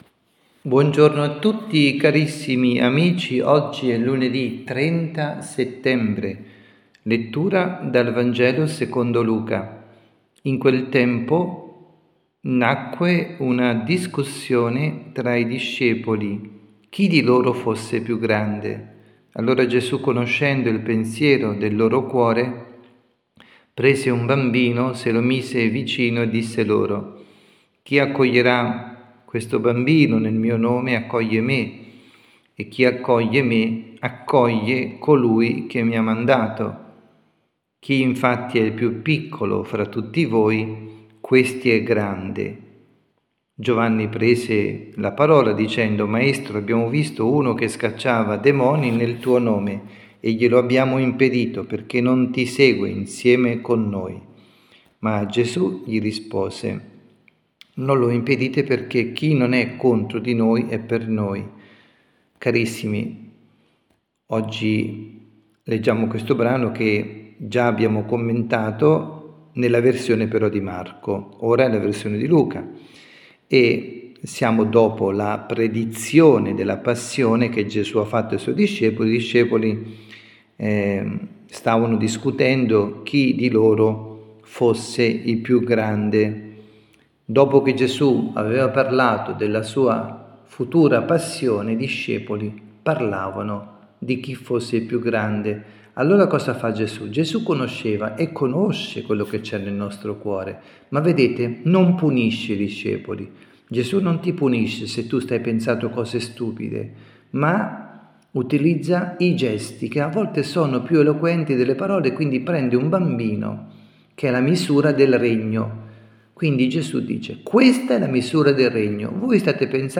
Catechesi
dalla Basilica di San Nicola – Tolentino